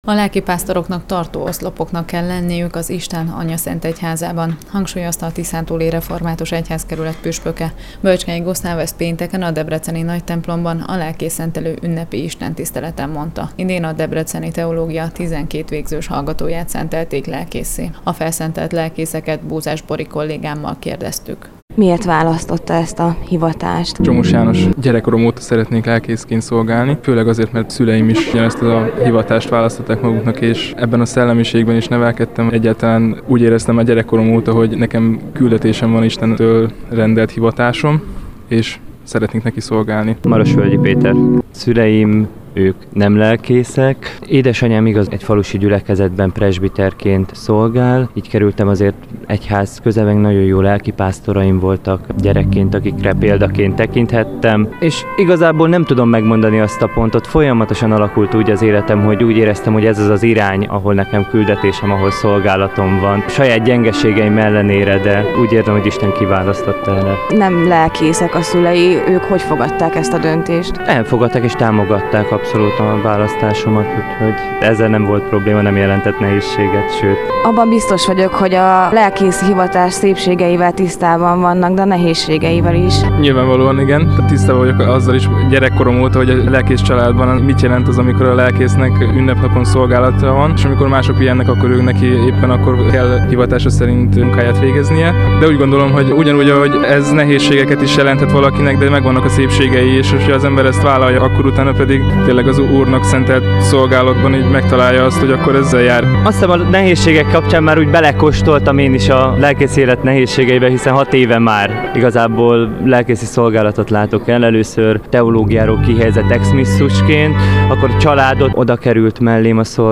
A lelkészszentelés része volt a Tiszántúli Református Egyházkerület éves közgyűlésének, amelyet az ünnep alkalmon bezártak. A helyszínen készült riport-összeállítást itt hallgathatja meg.